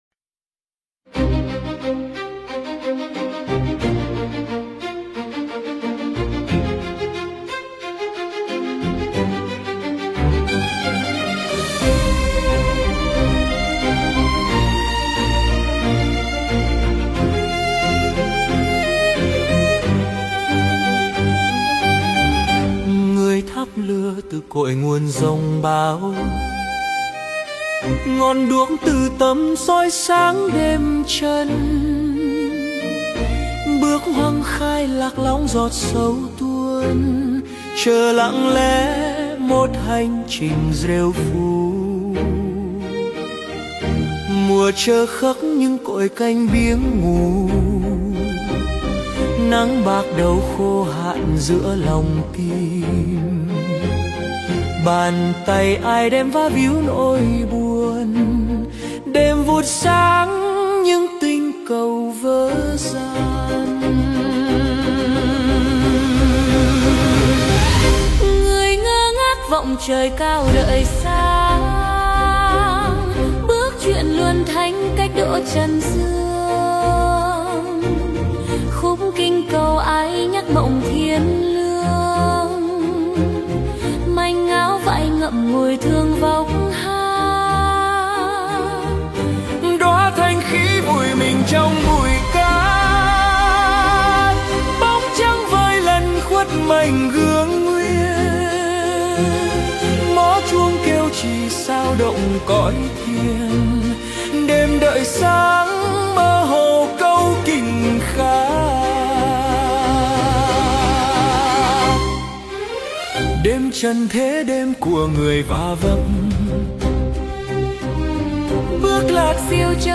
528. Nhạc thiền (số 05) - Vị Lai Pháp
Phổ nhạc: Suno AI